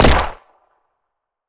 gun_pistol_shot_01.wav